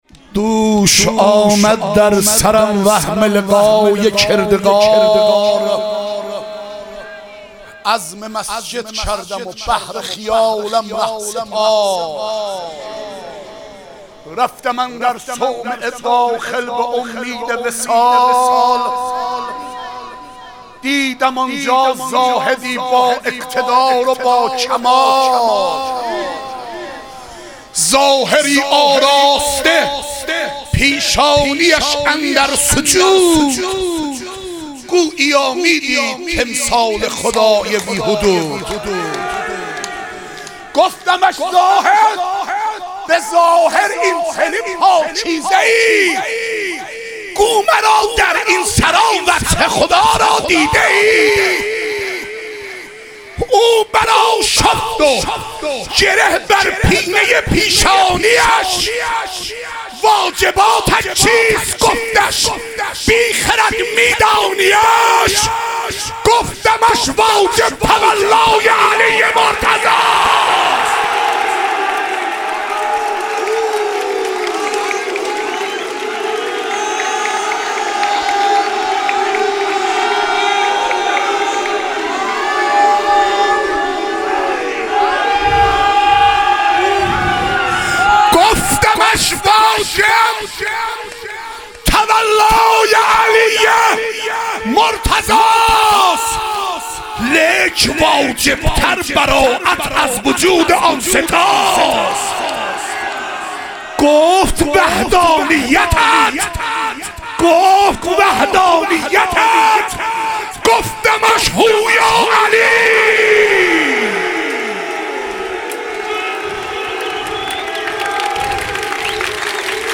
عید سعید غدیر خم - مدح و رجز